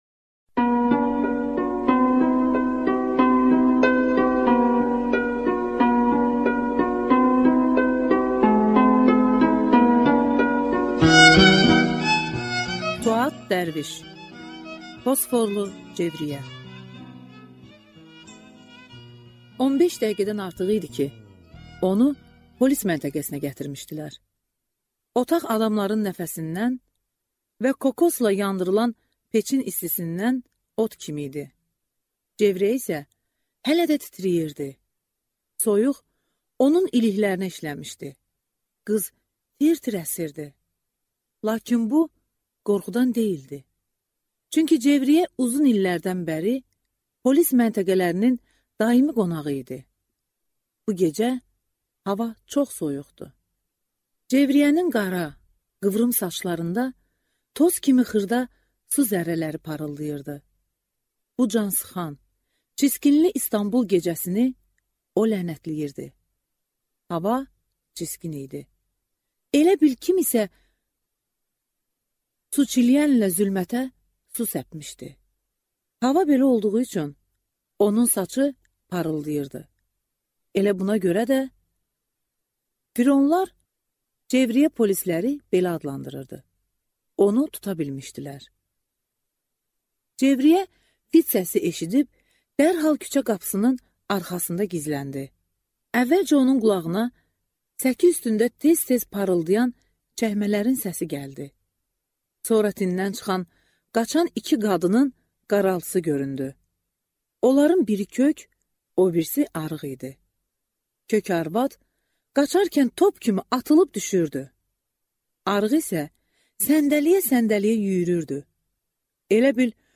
Аудиокнига Fosforlu Cevriyyə | Библиотека аудиокниг